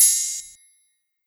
Open Hats
YCSizzleOpenTriangle.wav